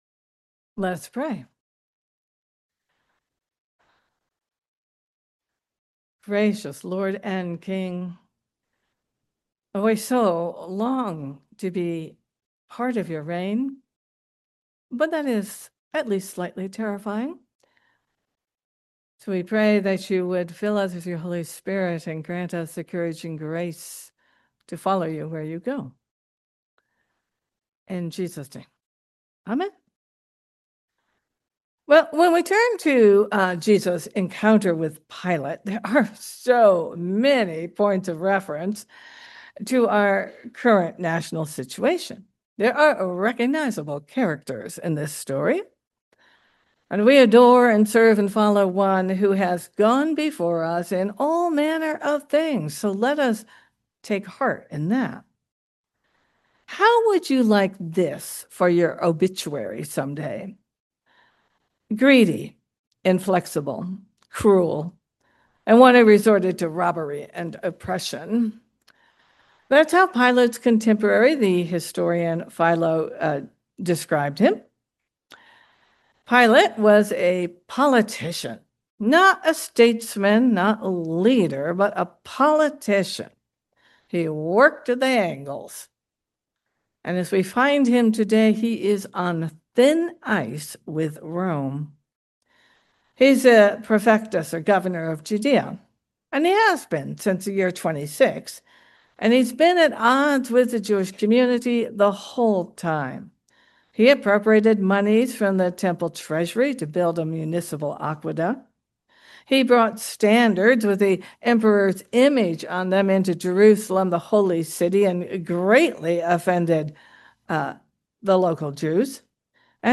Raleigh Mennonite Church